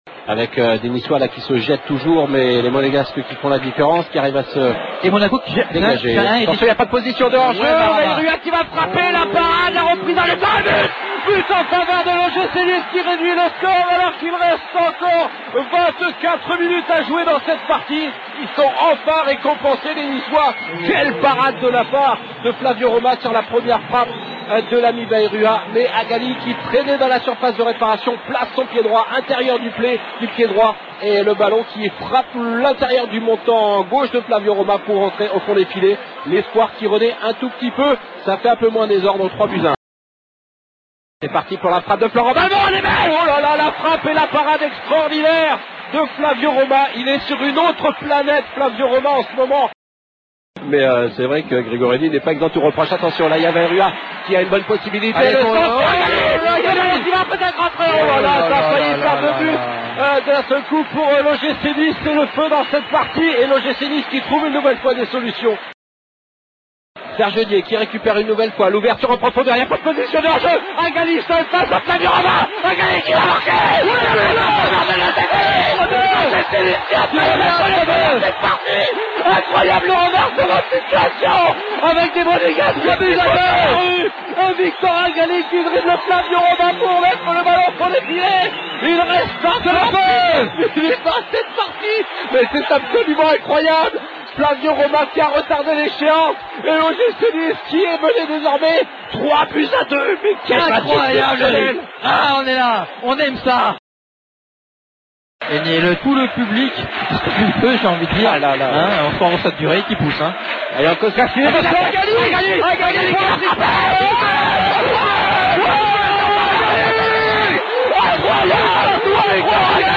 Commentaire : A 24 minutes de la fin du match, l'OGC Nice est mené 3-0. Ils gagneront 3-4 avec un triplé de Victor Agali. Les meilleurs moments en direct sur Sud Radio.